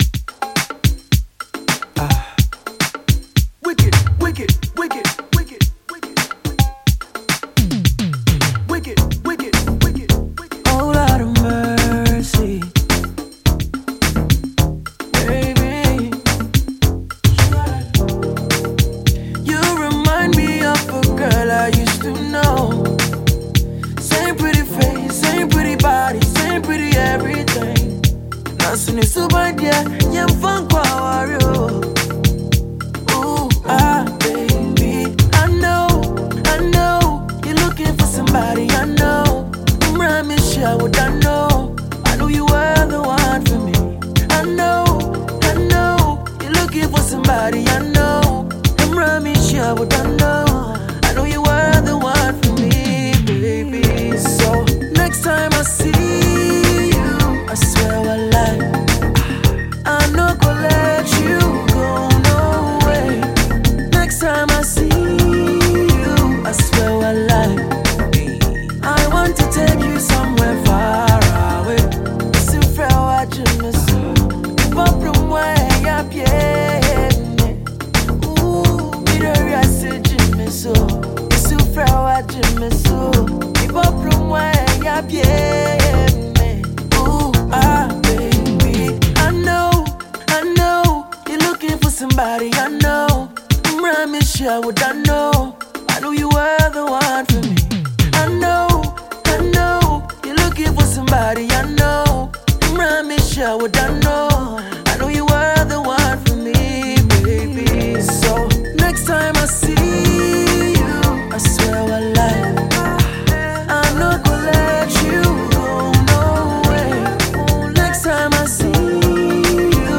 Ghanaian singer